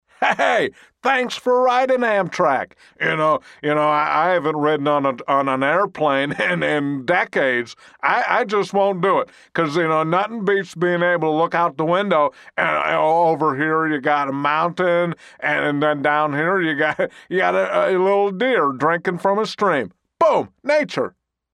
Tags: celebrity announcements, celebrity impersonator, impressionist for hire, safety announcement voice